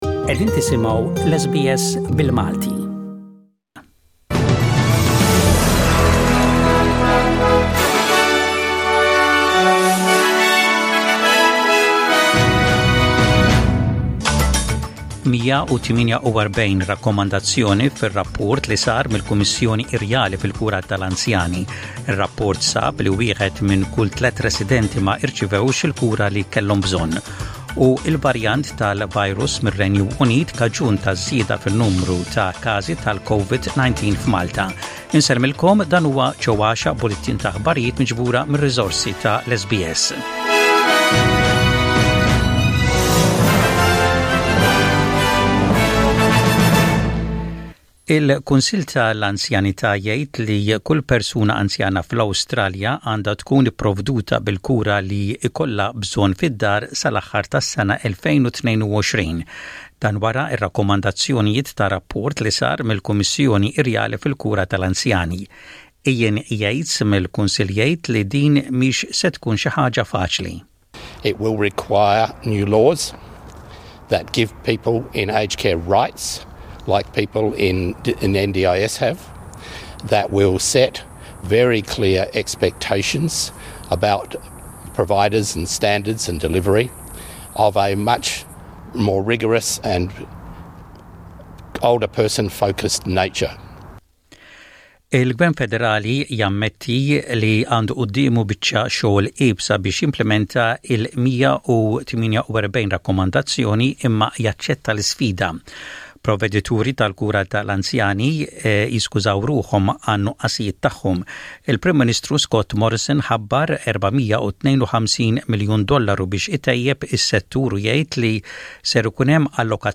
Maltese News